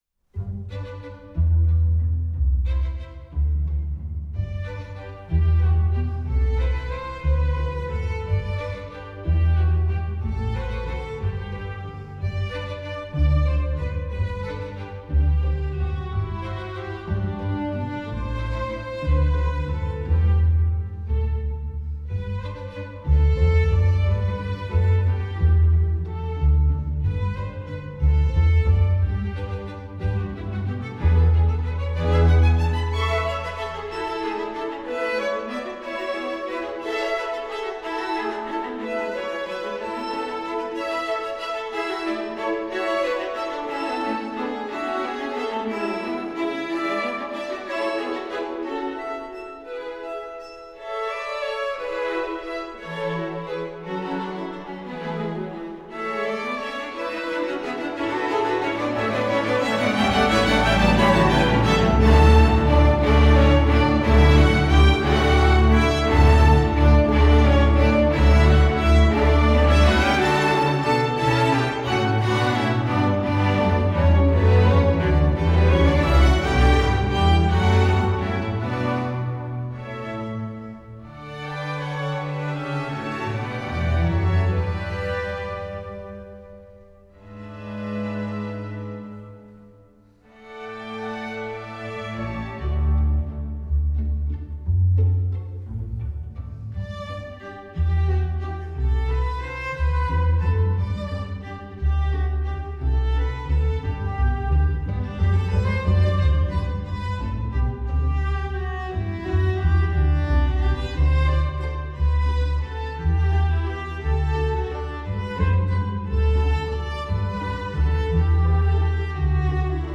Tweet Type: String Orchestra Tags